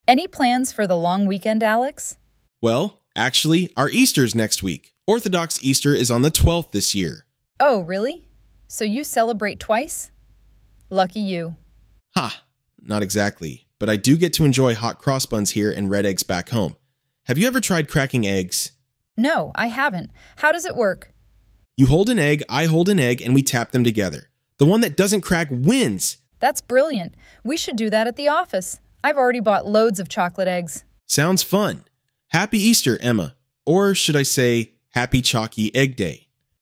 Emma and Alex are colleagues at work. Emma is British, Alex is Romanian. It's the Friday before Easter.
ElevenLabs_Easter_EduMNC.mp3